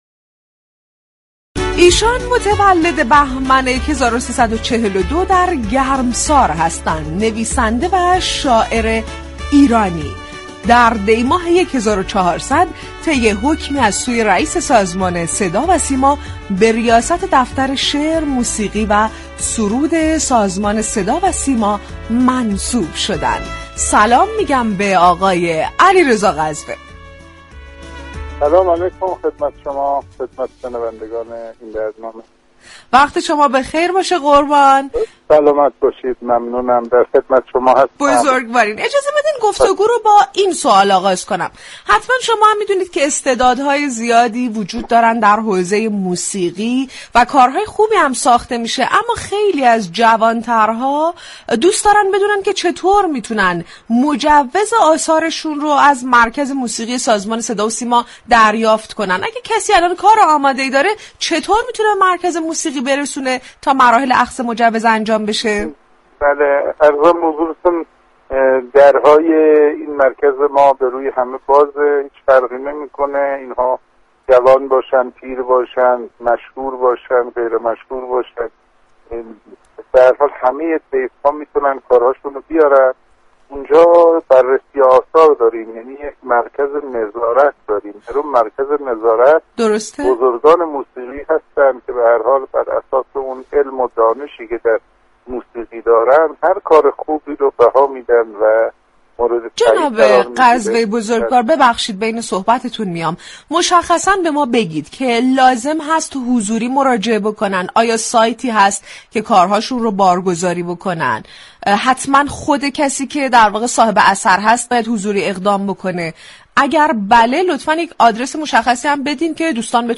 مدیردفترشعر، موسیقی و سرود سازمان صدا وسیما در گفتگو با رادیو صبا درباره مراحل دریافت مجوز پخش موسیقی در صدا و سیما توضیح داد